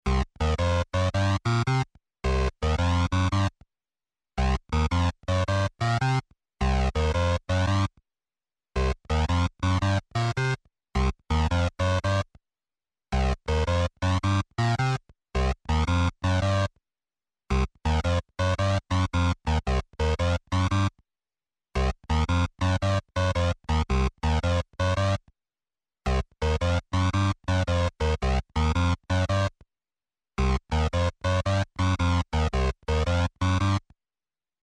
8 bit Gaming Musik
Tempo: langsam / Datum: 15.08.2019